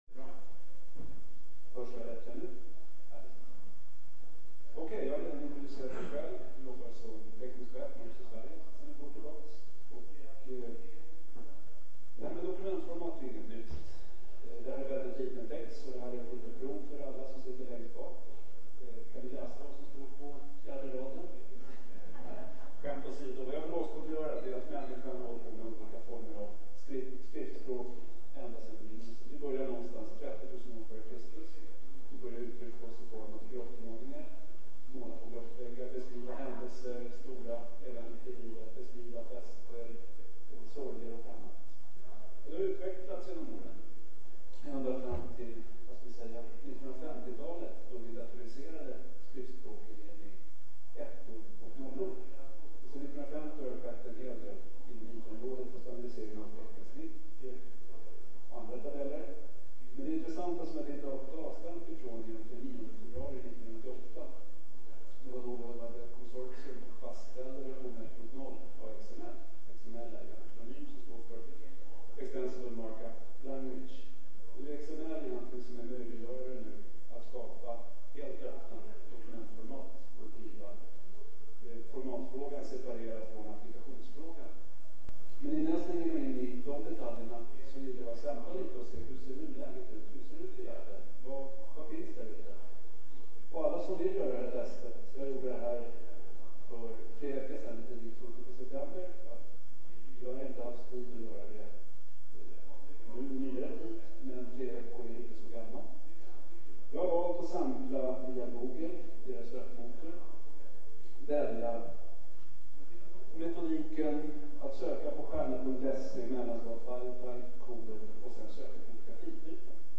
Vad inneb�r de nya formaten som introduceras med Windows Vista? I denna rundabordsdiskussion deltar representanter f�r Microsoft, r�relsen f�r �ppen programvara samt en utomst�ende expert.